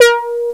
014_MBRASS.WAV.wav